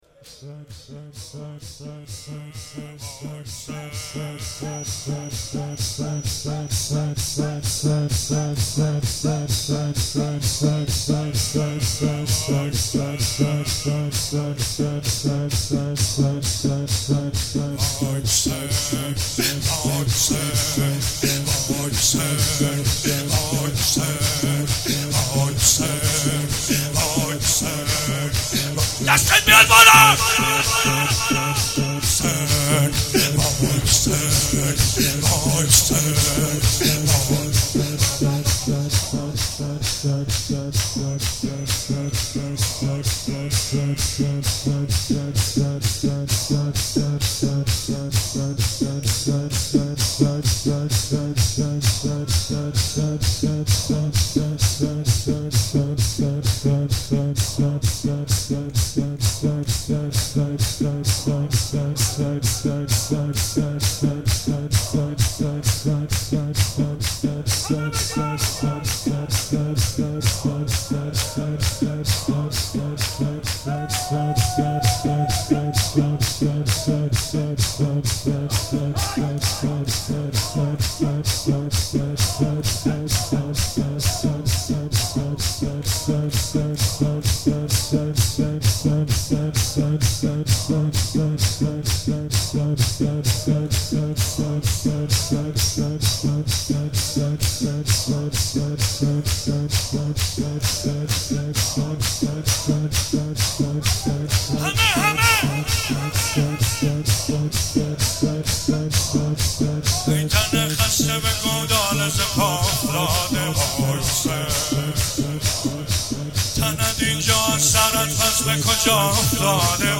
مراسم عزاداری شام غریبان 94
چهار راه شهید شیرودی، حسینیه حضرت زینب (سلام الله علیها)
شور- ای تن خسته به گودال ز پا افتاده